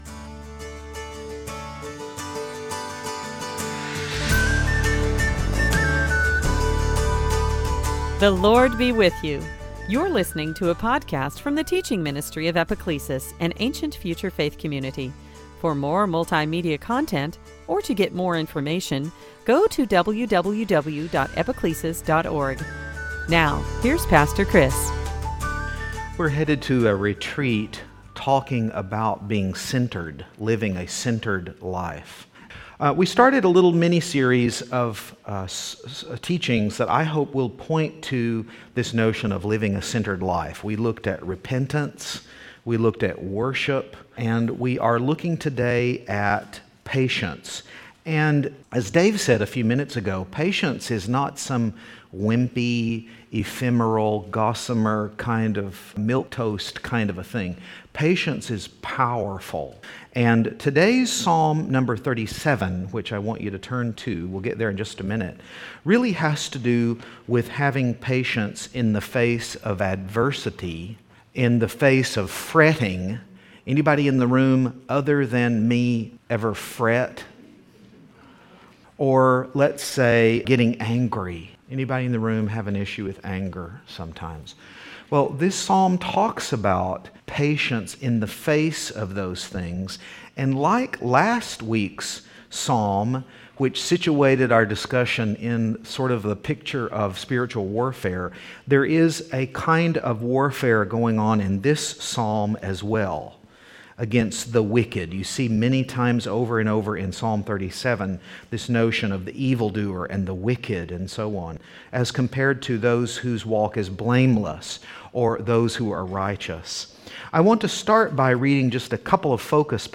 2016 Sunday Teaching church growth early church living centered patience Season after Pentecost